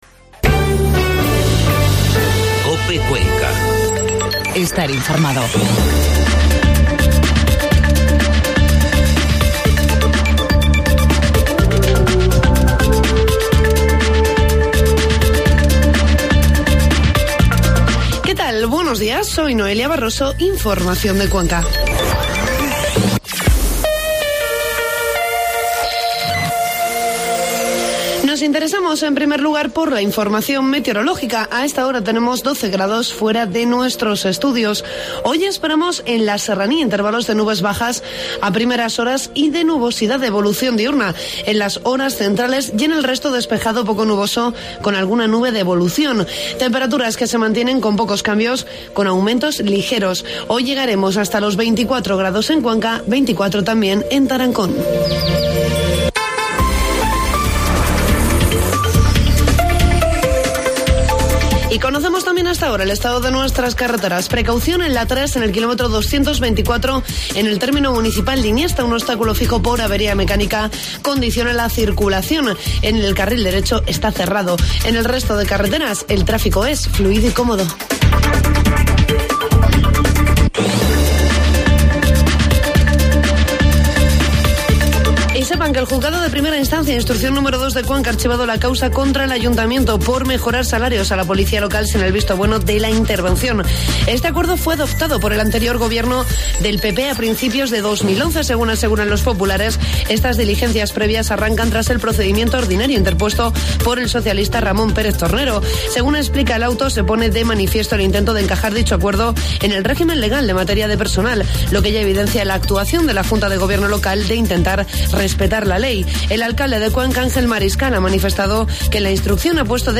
AUDIO: Informativo matinal 13 de junio.